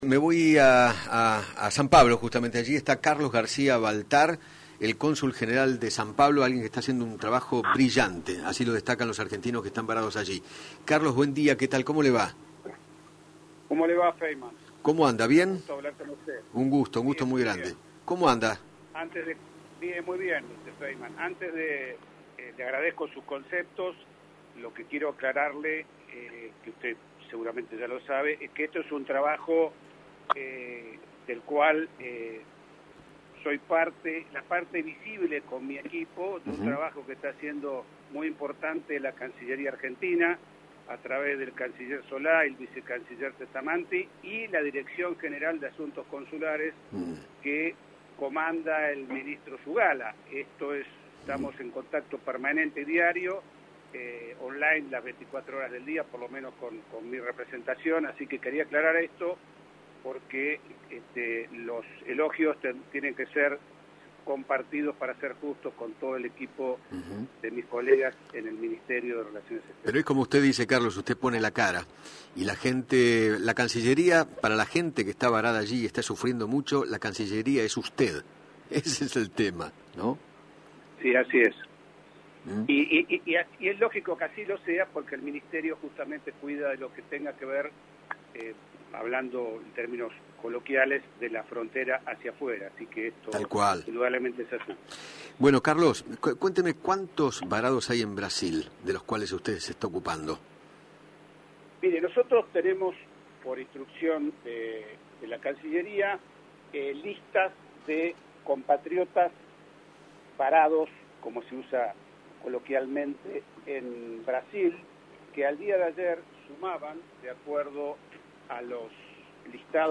Carlos García Baltar, Consul General Argentino en San Pablo, Brasil,dialogó con Eduardo Feinmann sobre el trabajo que está llevando a cabo para que los los argentinos varados allí regresen al país.